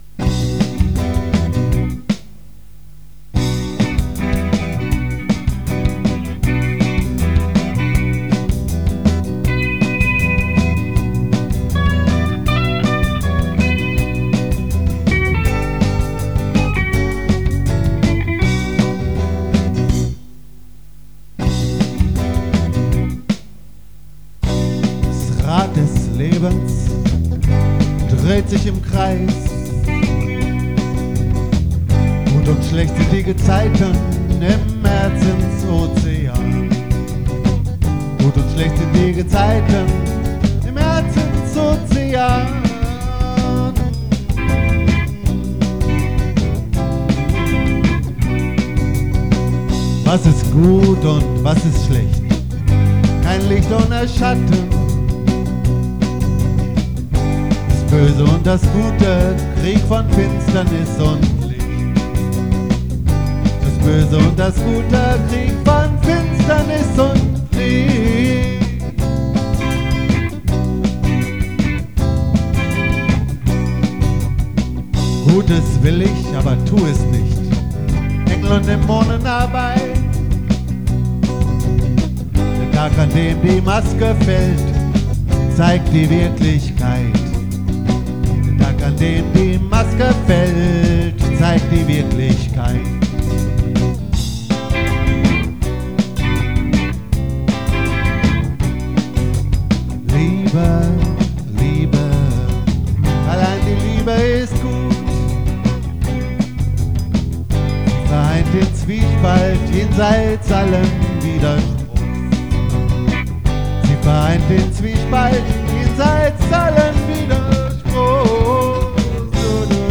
Keyboards und Vocal
Gitarre
Bass
Drums